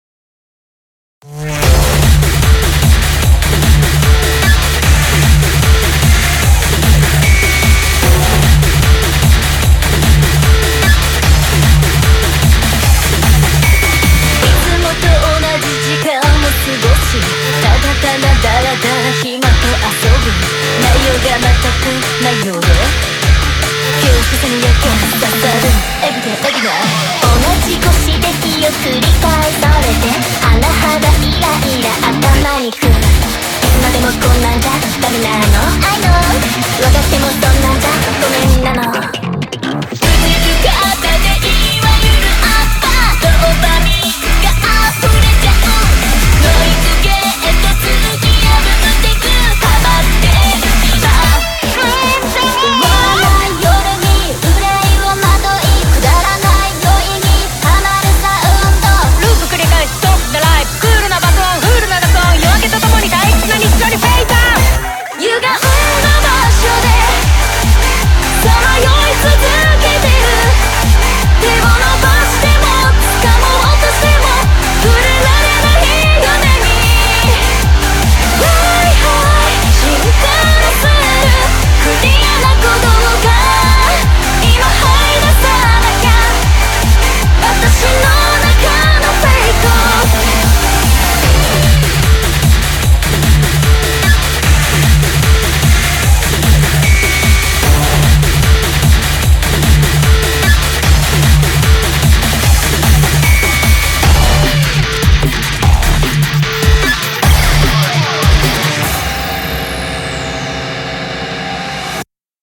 BPM150
Audio QualityCut From Video